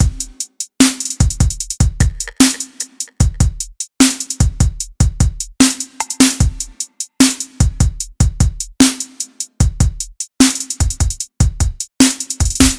遥远的地方鼓
描述：看看这个鼓的钢琴。
Tag: 150 bpm Hip Hop Loops Drum Loops 2.15 MB wav Key : Unknown